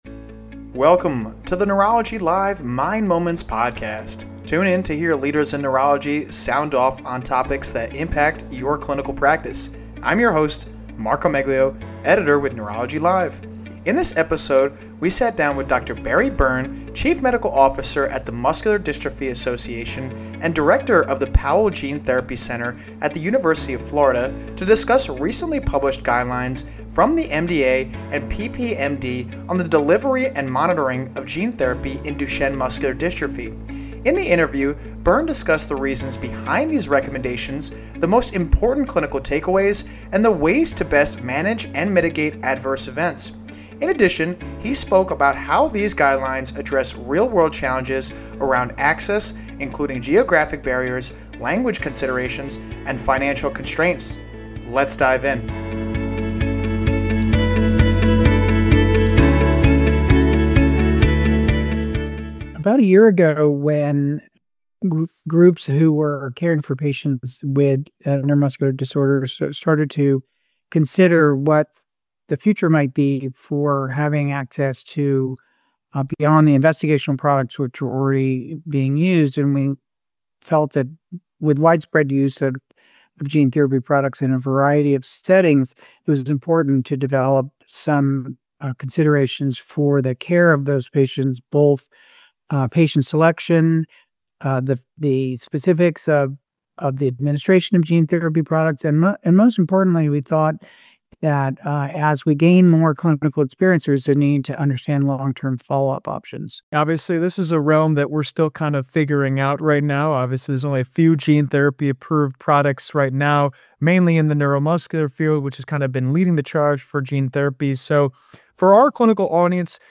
Tune in to hear leaders in neurology sound off on topics that impact your clinical practice.